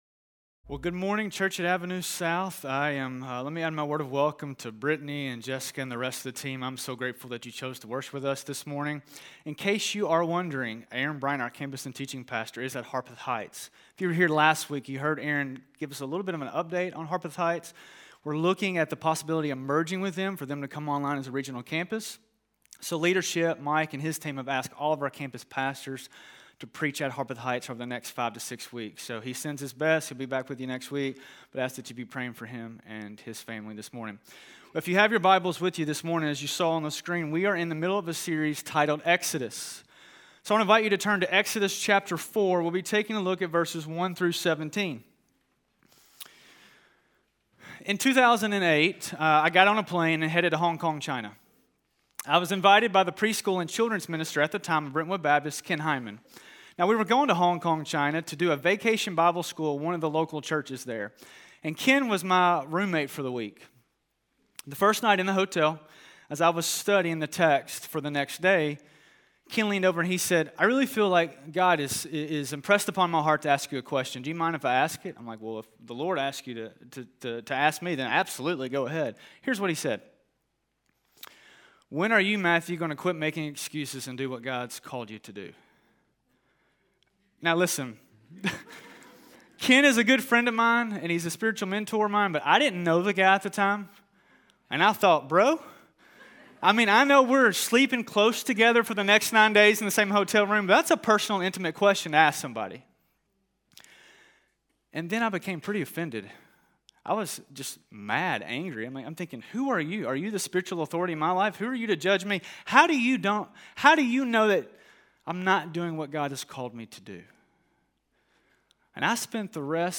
Scripture